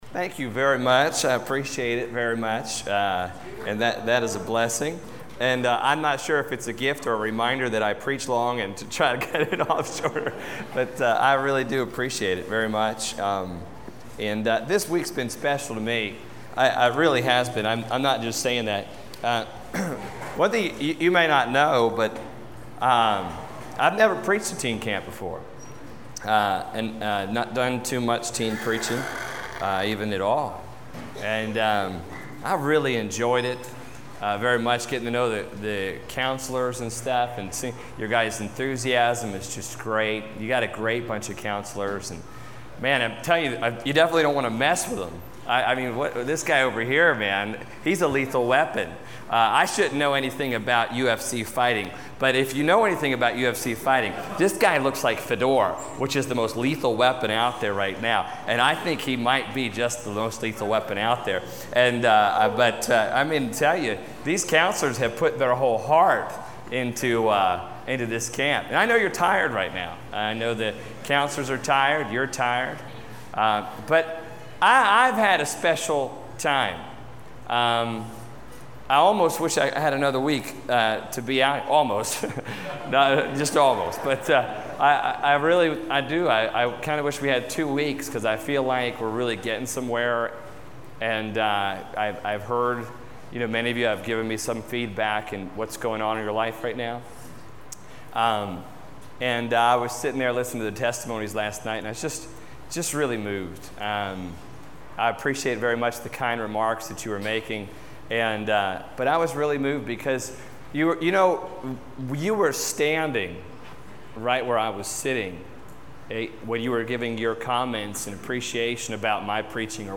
Service Type: Teen Camp